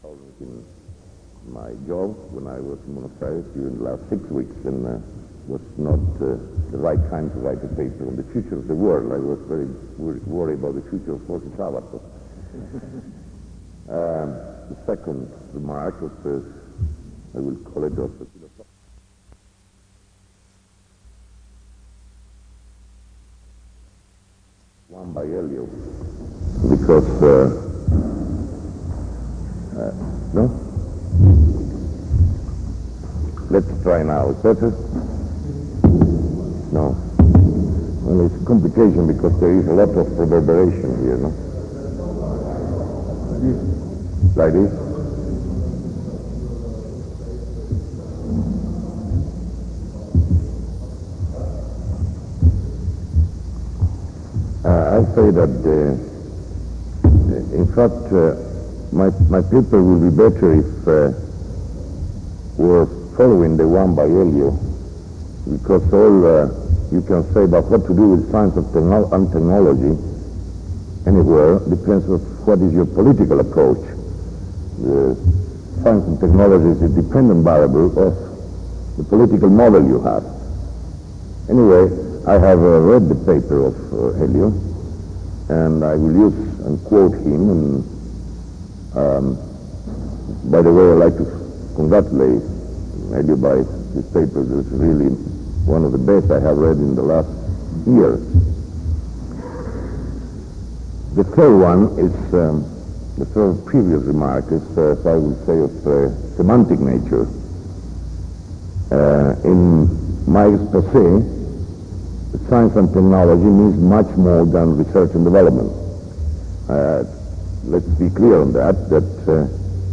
Discurso realizado en Río de Janeiro el 31/07/1971.